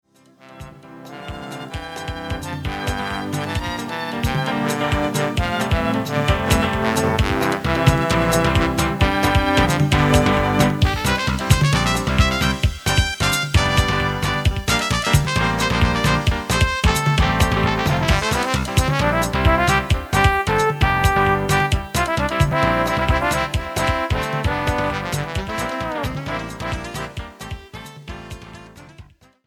Writing of modern style Jazz.